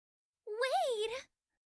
Anime Girl Wait Sound Effect Download: Instant Soundboard Button